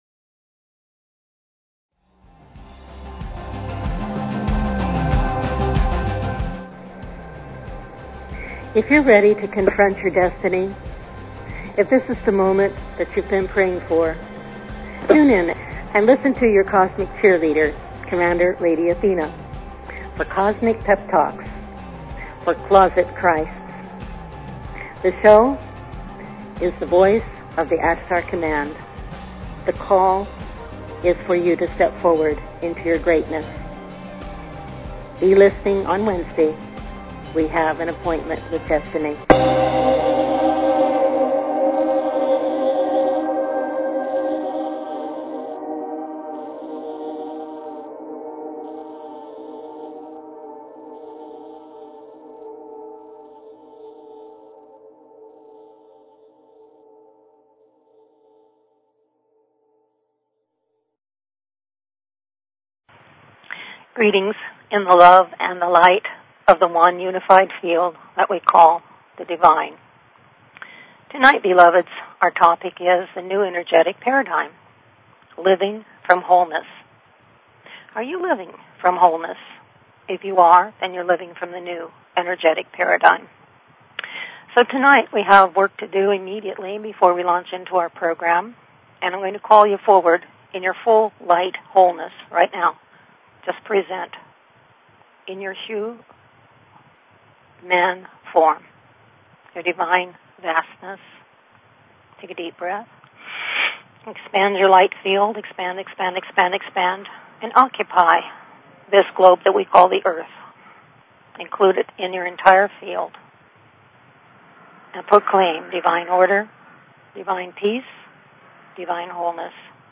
Talk Show Episode, Audio Podcast, The_Voice_of_the_Ashtar_Command and Courtesy of BBS Radio on , show guests , about , categorized as
Various experiential processes, meditations and teachings evoke your Divine knowing and Identity, drawing you into deeper com